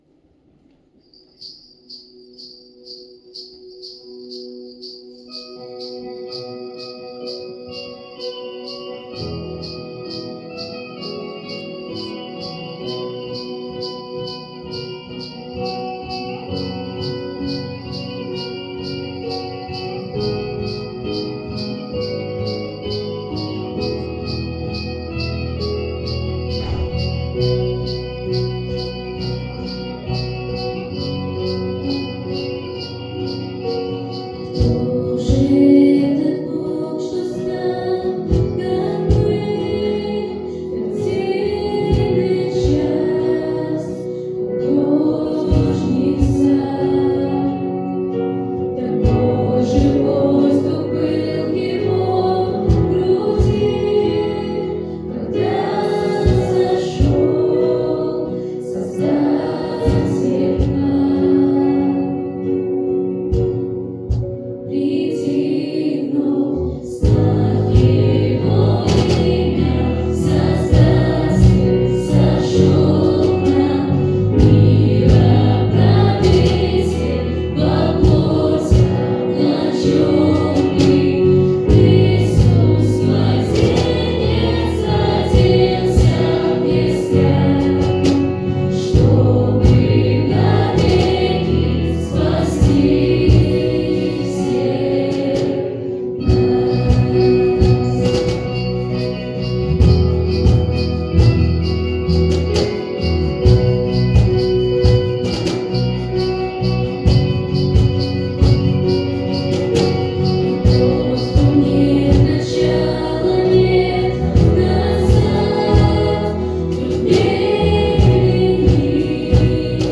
69 просмотров 60 прослушиваний 2 скачивания BPM: 132